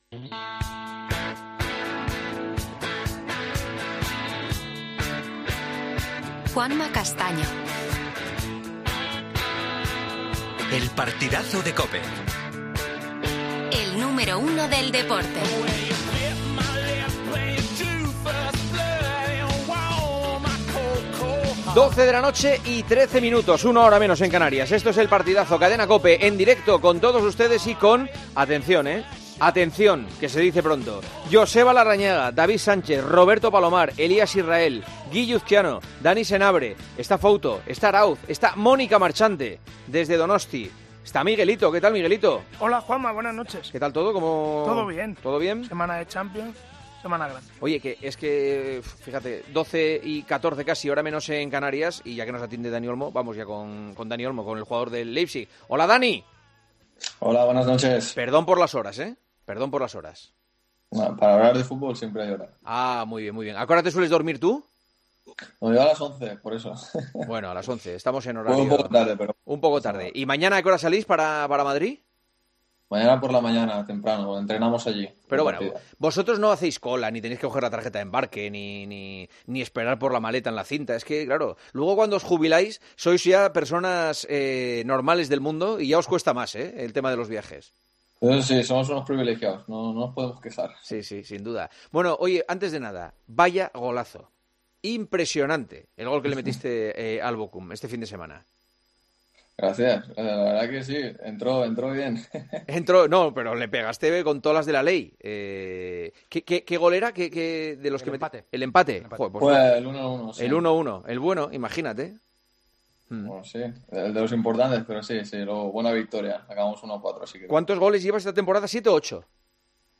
Juanma Castaño entrevistó al delantero de Leipzig, a dos días de su partido contra el Real Madrid en la vuelta de los octavos de final de Champions.